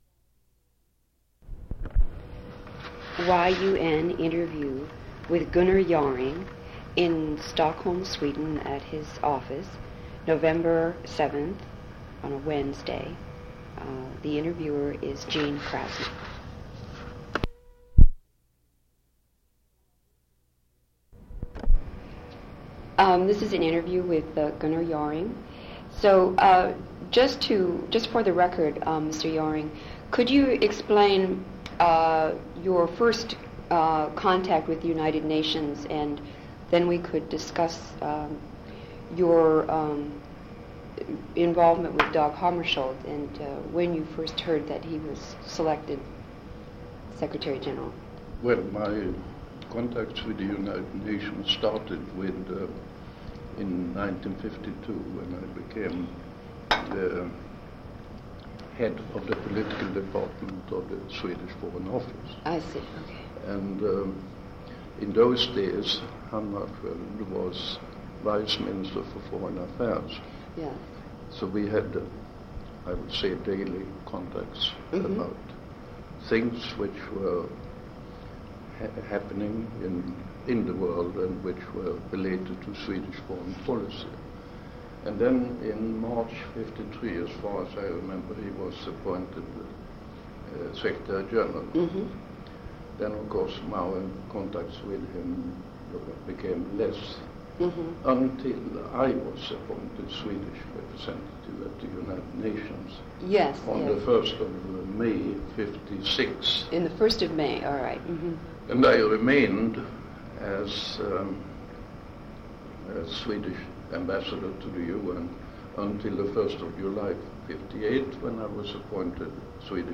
Interview with Gunnar Jarring / - United Nations Digital Library System